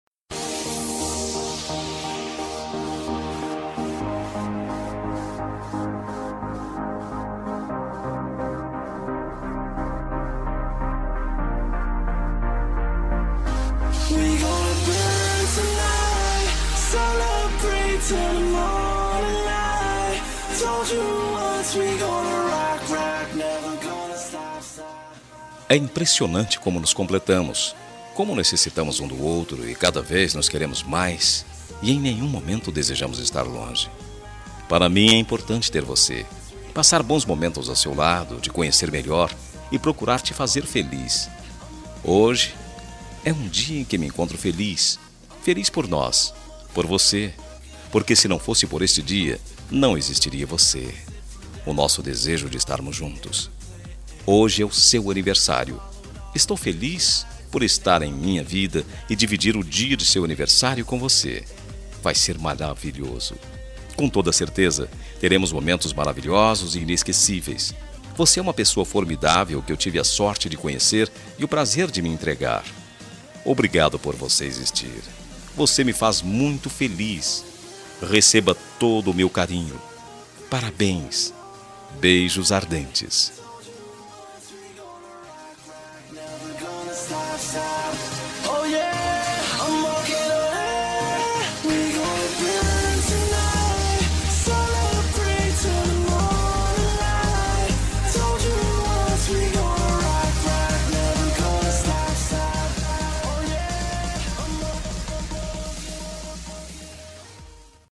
Telemensagem de Aniversário Romântico – Voz Masculina – Cód: 202137 – Amante